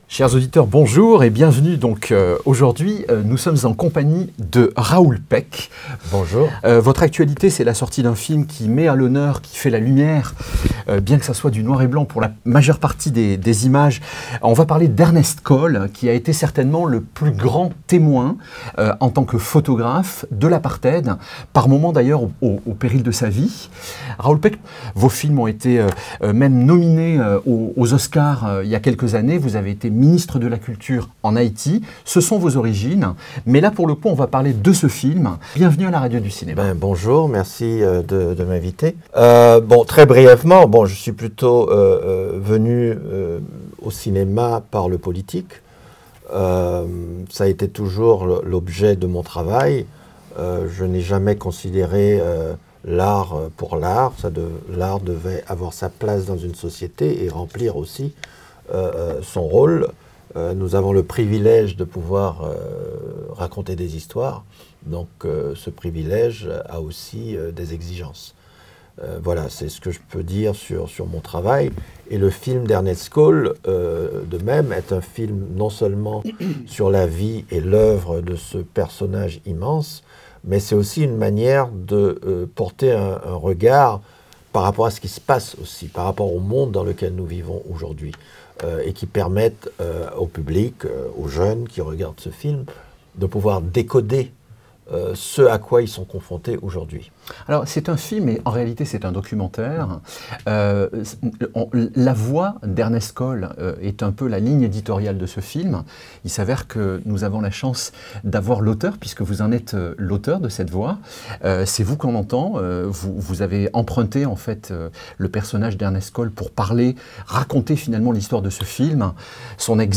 Une interview